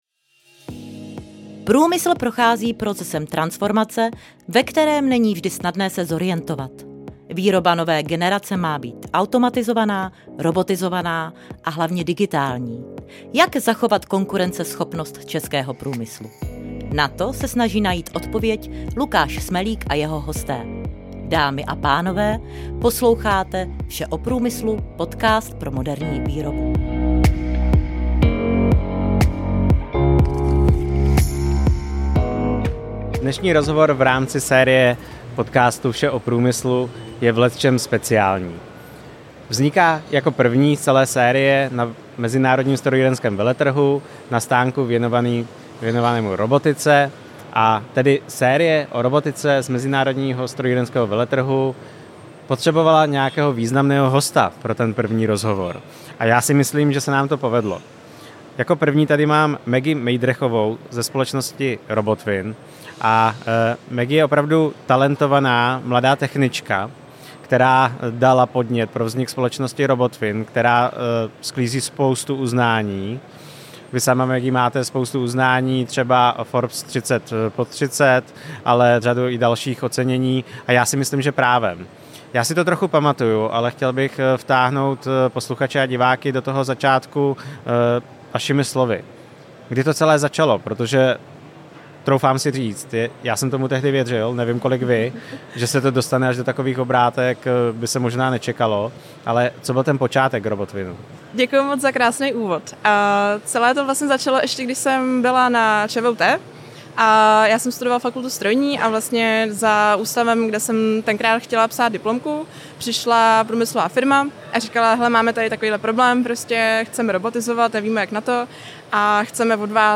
První ze série rozhovorů Vše o průmyslu na MSV na stánku věnovaného robotice přivítal významného hosta